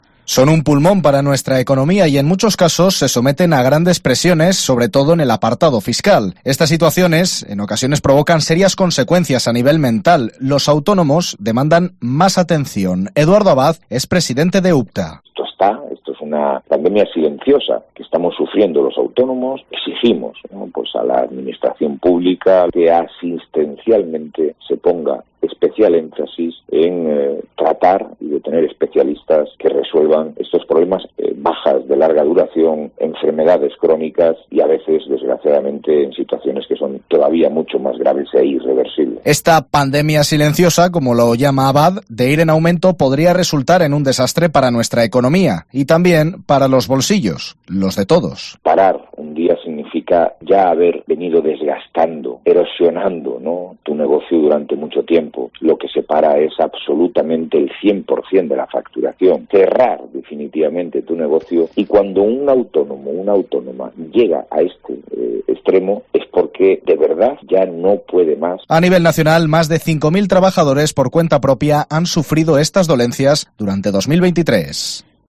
En la entrevista con COPE Cantabria